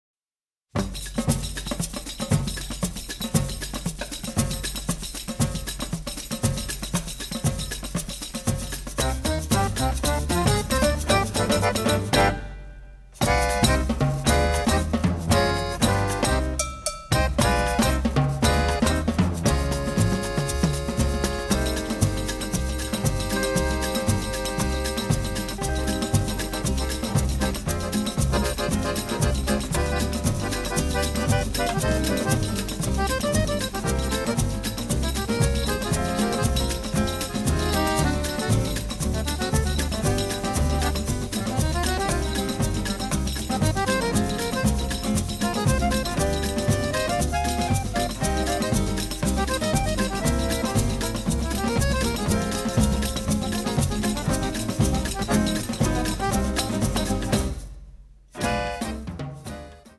Instrumental 1-28